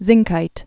(zĭngkīt)